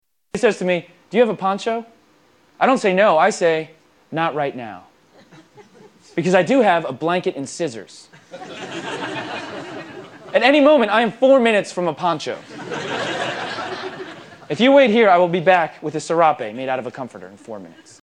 Category: Comedians   Right: Personal
Tags: Demetri Martin Demetri Martin clips Demetri Martin jokes Demetri Martin audio Comedian